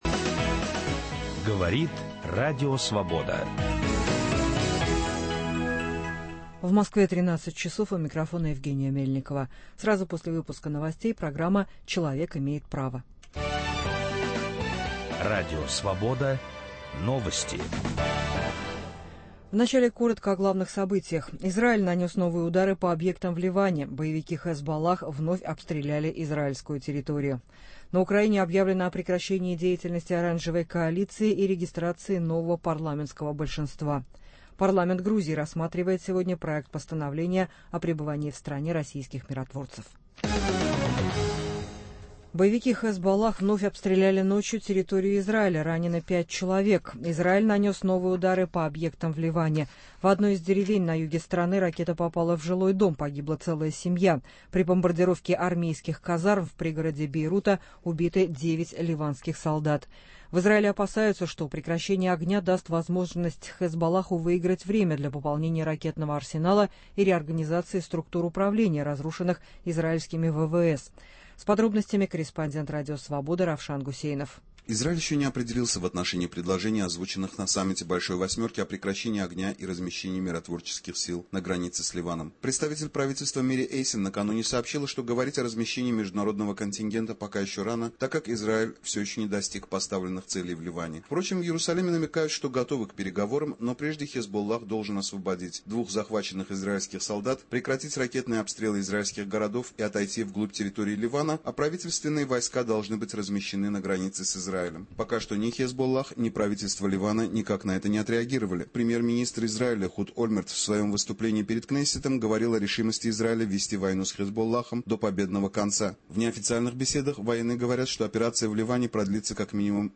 Участники передачи: писатель и публицист Юрий Болдырев, лидер партии "Демократический Союз" Валерия Новодворская и один из лидеров КПРФ Василий Шандыбин.